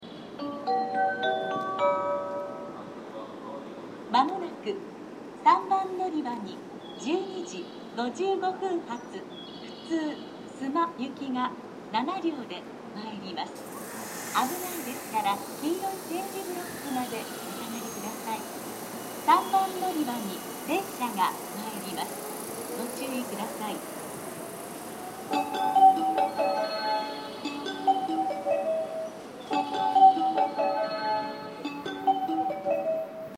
音量もほかの駅と比べて大きめ ですね。
３番のりばA：JR神戸線
接近放送普通　須磨行き接近放送です。